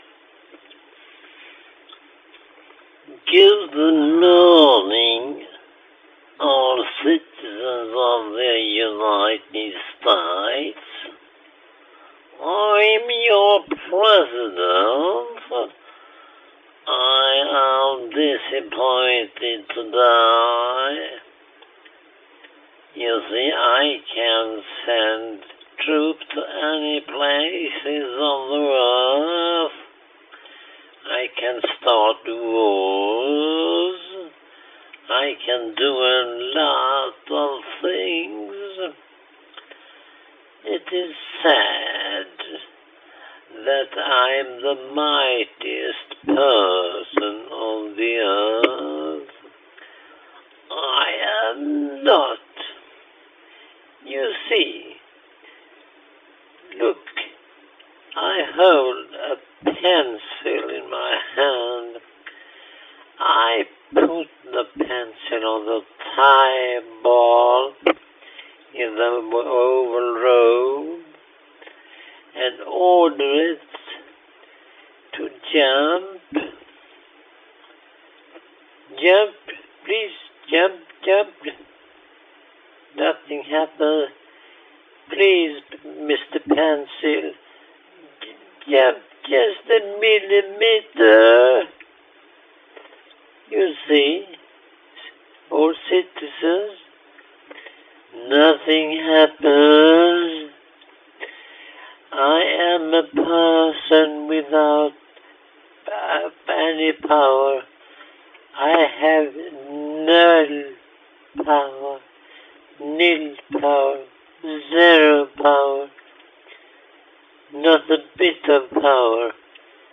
描述：编辑关于肯尼迪总统演讲的音频。
Tag: sounddesign 抽象的 大学 ACA德米 效果 肯尼迪 声音